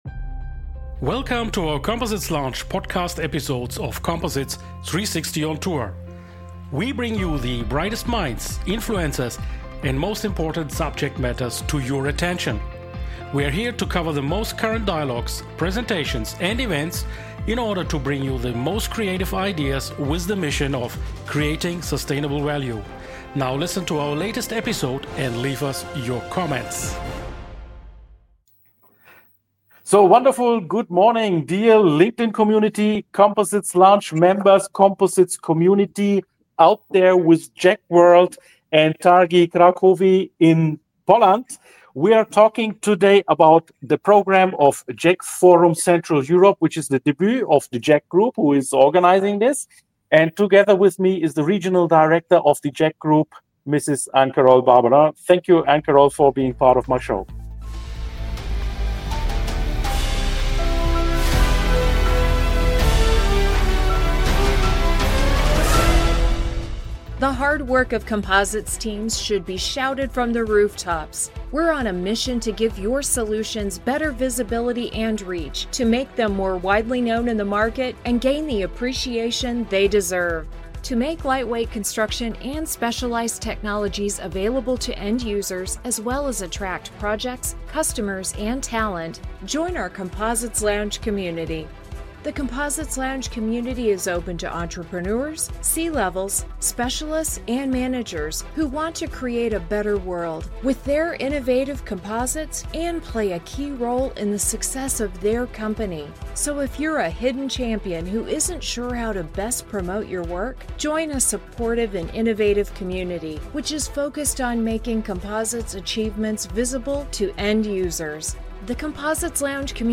#178 JEC Forum Central Europe Live Talk with JEC Group & Targi w Krakowie ~ COMPOSITES LOUNGE - Das Online Experten Netzwerk Podcast